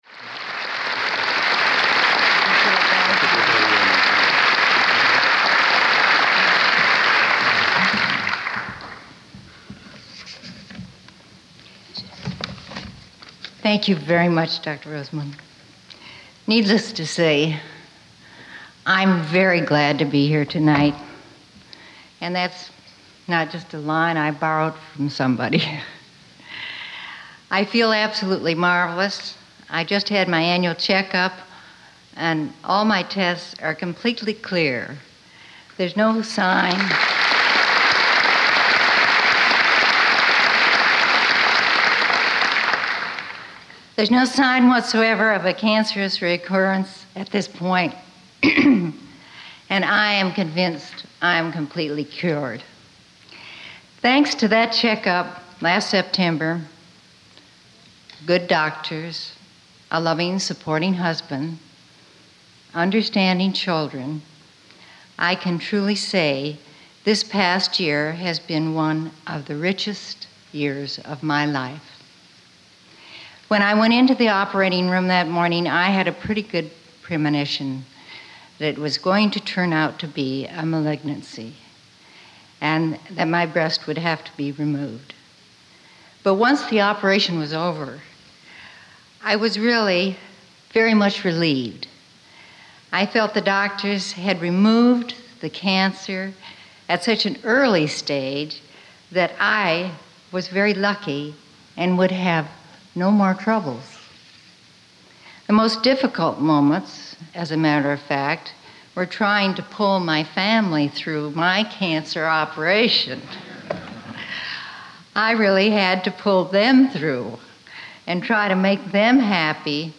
Address to an American Cancer Society Awards Dinner
delivered 7 November 1975, Waldorf Astoria Hotel, New York, NY